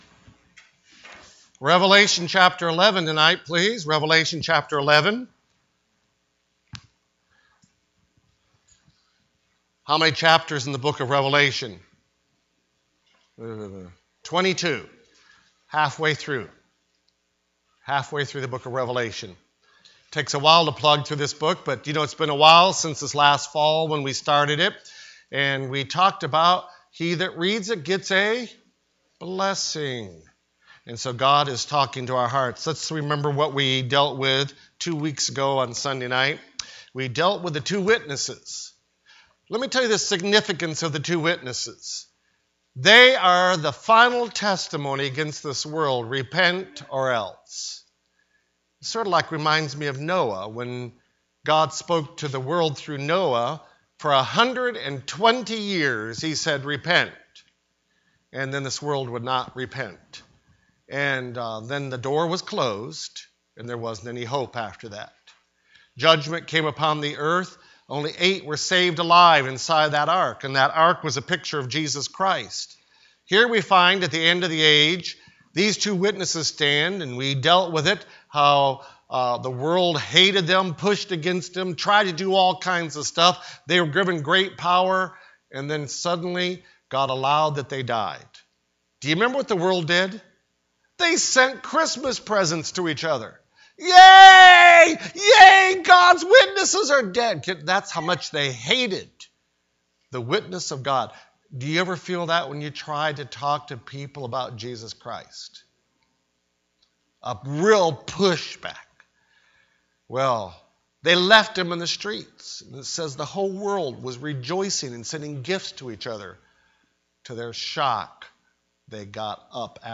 The Revelation Service Type: Sunday Evening Preacher